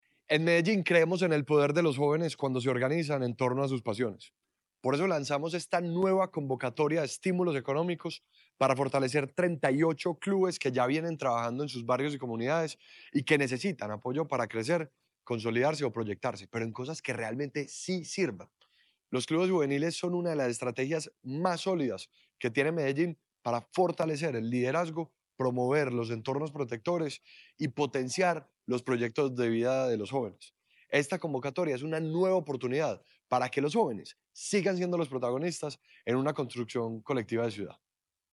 Declaraciones secretario de la Juventud, Ricardo Jaramillo Vélez
Declaraciones-secretario-de-la-Juventud-Ricardo-Jaramillo-Velez.mp3